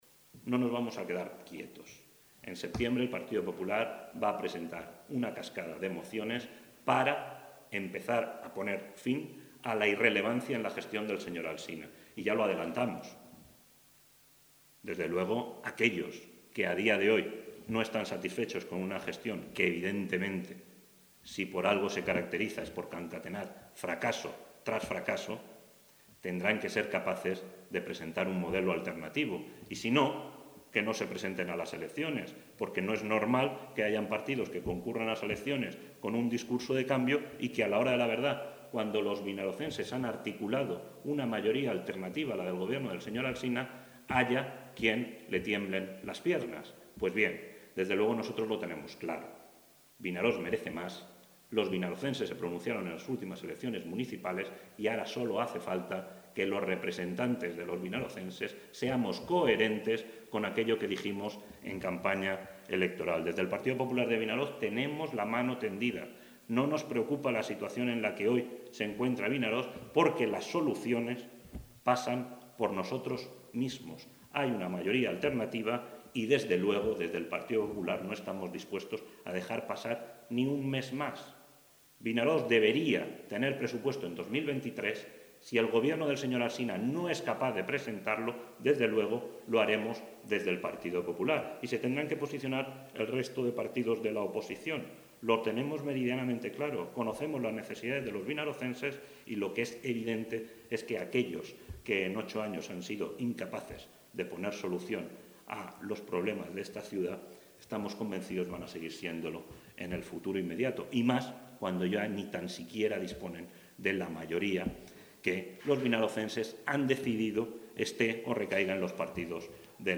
Juan Amat, portaveu del PP a Vinaròs
En una roda de premsa convocada per donar inici al curs polític, Amat ha recordat que fa poc més d’un mes, l’equip de govern acusava la oposició de «tenir segrestat l’Ajuntament» simplement per no haver votat segons les seves preferències.